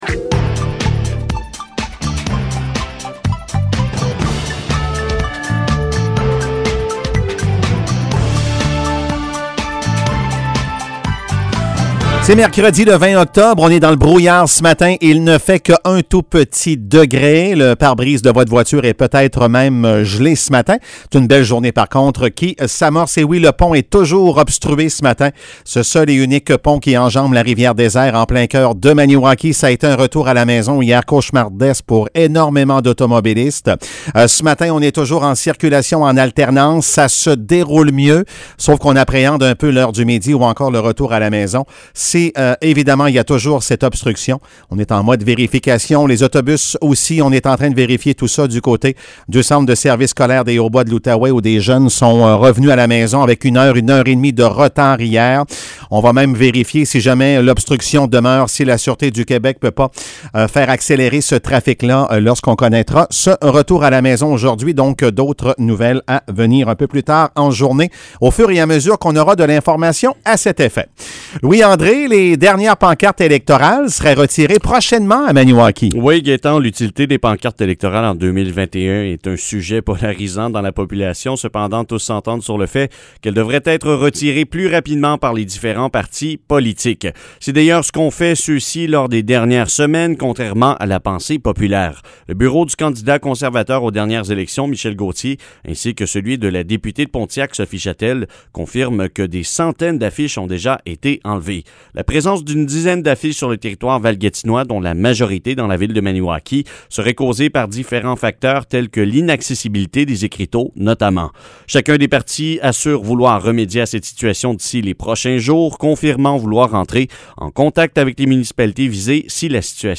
Nouvelles locales - 20 octobre 2021 - 8 h